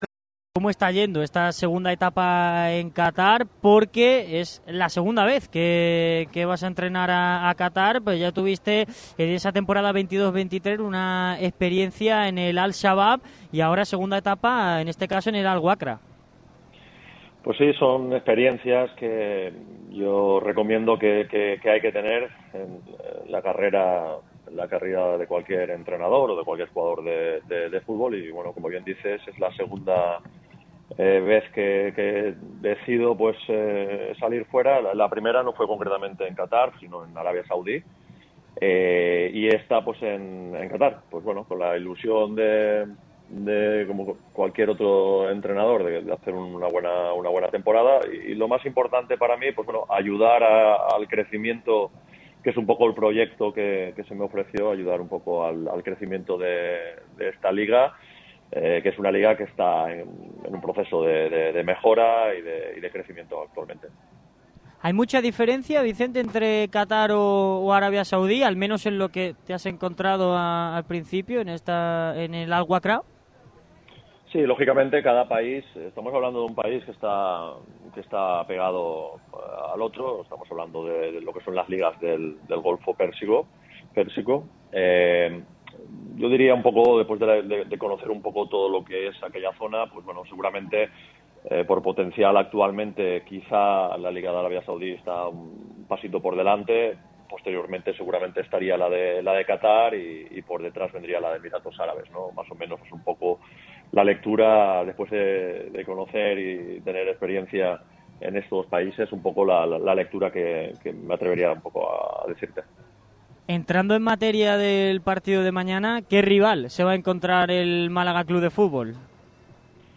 El entrenador que será rival del Málaga CF este miércoles ha concedido una entrevista en exclusiva a Radio MARCA Málaga.